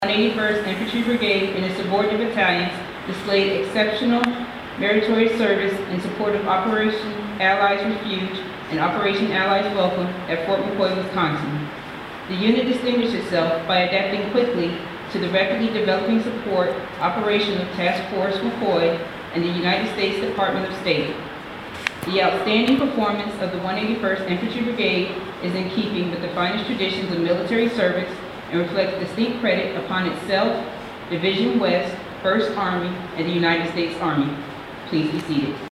181st Multi-Functional Training Brigade Meritorious Unit Commendation Ceremony, Part III
Soldiers with the 181st Multi-Functional Training Brigade and from units within the brigade participate in an Army Meritorious Unit Citation ceremony Dec. 14, 2023, at Fort McCoy, Wis. During the ceremony, the 181st and its units received the citation for their support for Operation Allies Refuge/Operation Allies Welcome (OAR/OAW) that took place at Fort McCoy from August 2021 to February 2022. The citation was officially presented to the unit by Brig. Gen. William Ryan, First Army Division West commanding general.